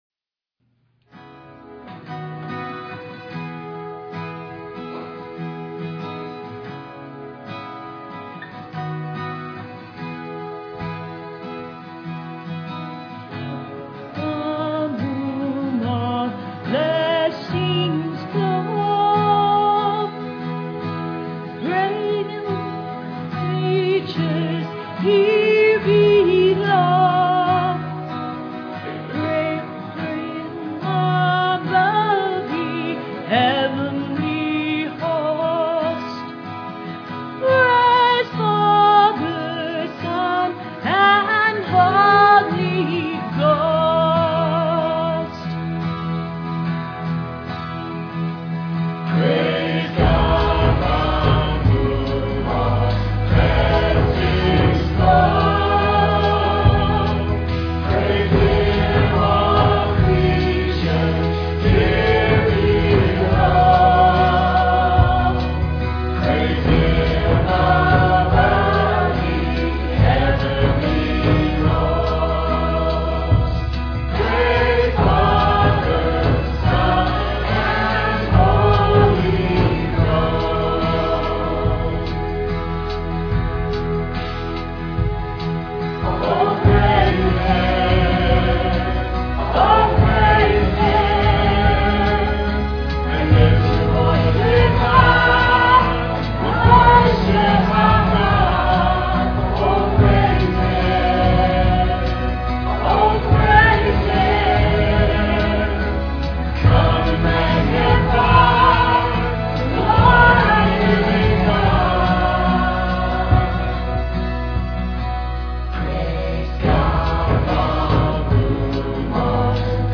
solo
Piano and organ offertory